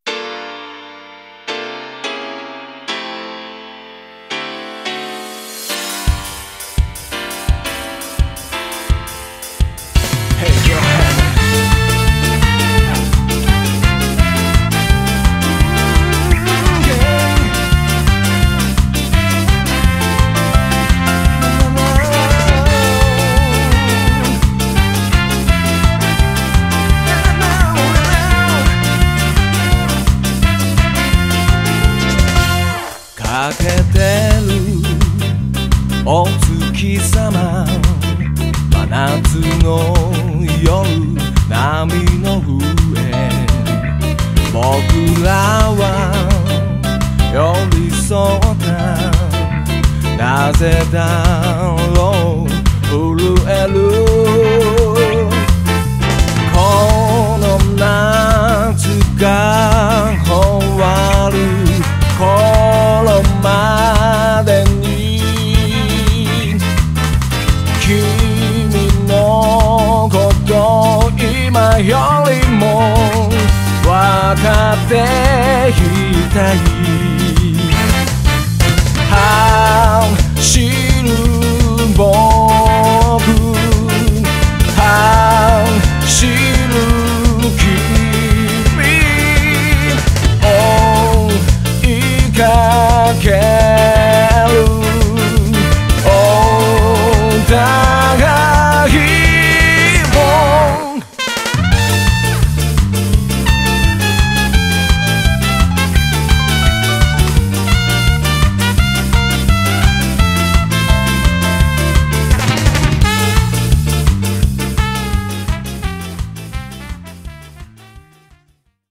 BPM170
Audio QualityPerfect (High Quality)
Relaxing, yet super upbeat!